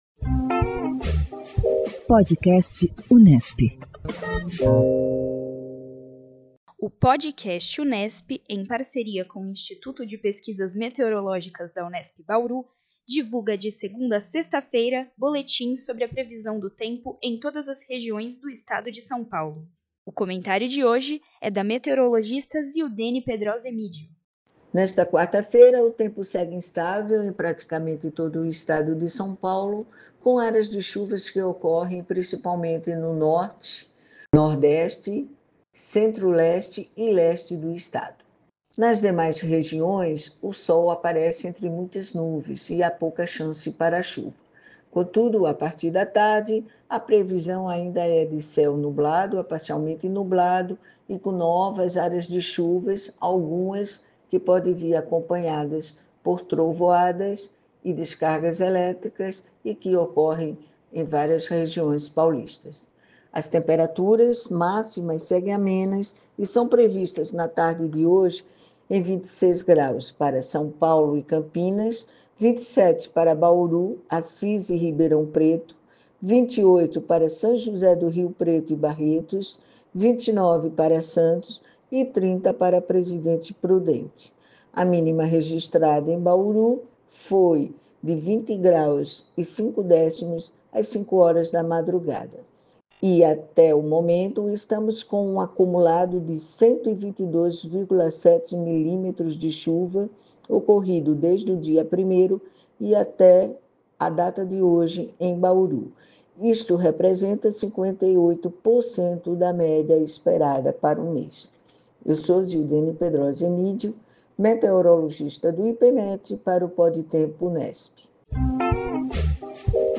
O Podcast Unesp, em parceria com o Instituto de Pesquisas Meteorológicas da Unesp, divulga diariamente boletins sobre a previsão do tempo em todas as regiões do Estado de São Paulo.